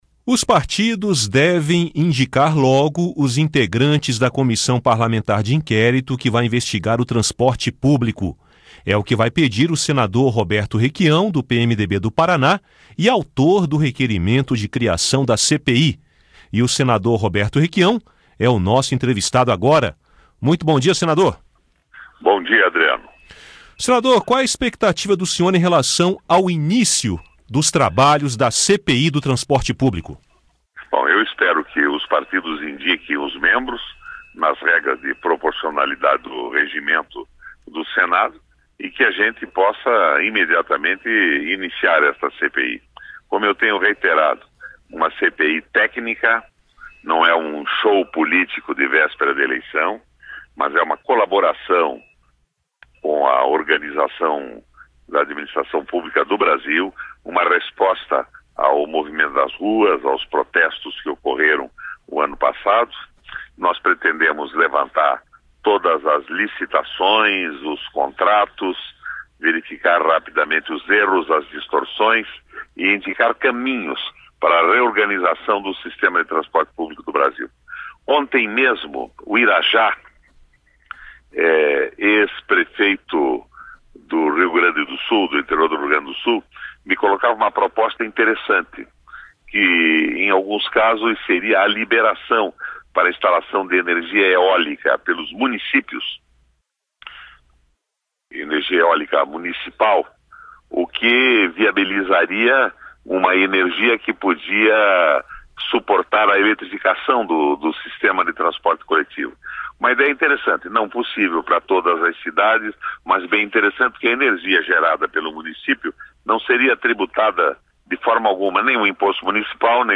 Entrevista com o senador Roberto Requião (PMDB-PR).